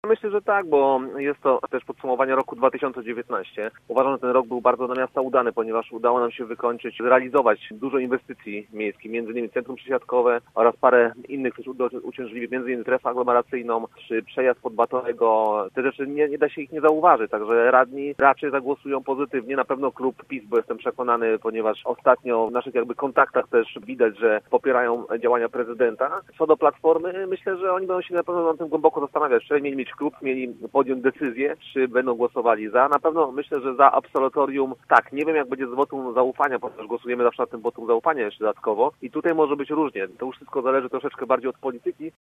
Czy prezydent otrzyma absolutorium? Komentarz F. Gryko
Filip Gryko był gościem Rozmowy Punkt 9.